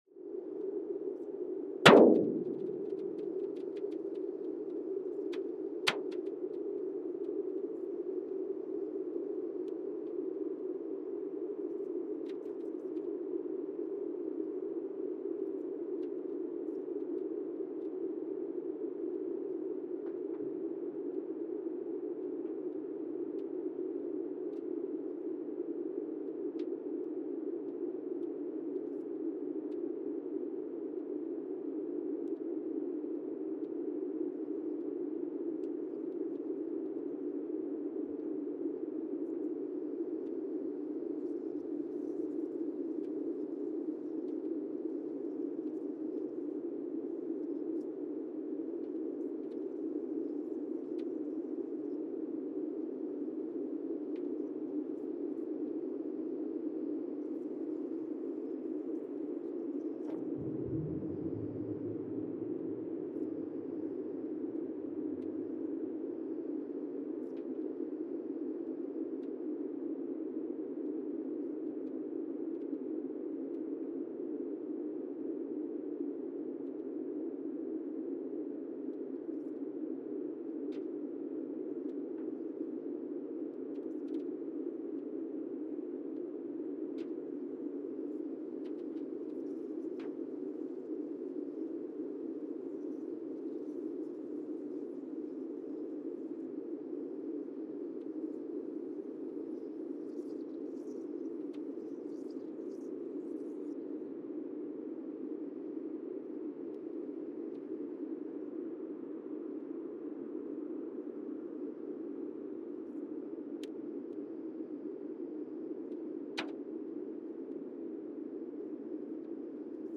Monasavu, Fiji (seismic) archived on January 28, 2020
No events.
Sensor : Teledyne Geotech KS-54000 borehole 3 component system
Speedup : ×1,800 (transposed up about 11 octaves)
Loop duration (audio) : 05:36 (stereo)